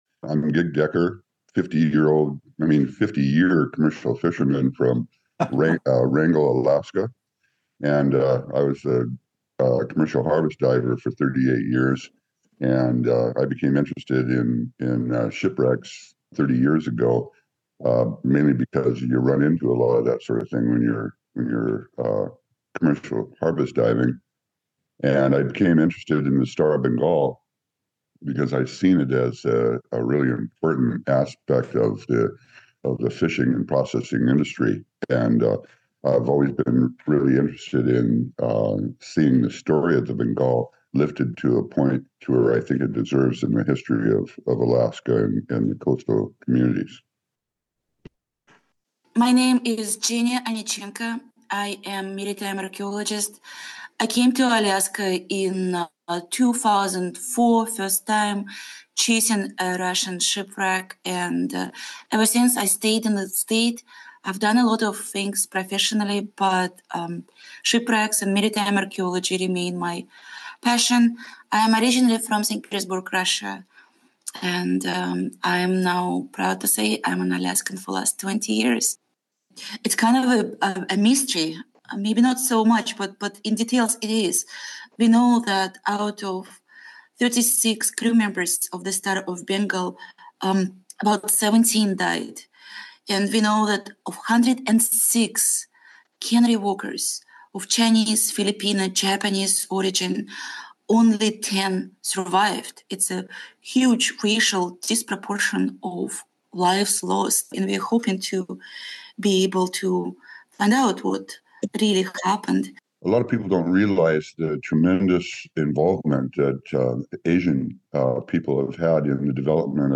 Tongass Voices: Southeast Alaska shipwreck researchers on setting the record straight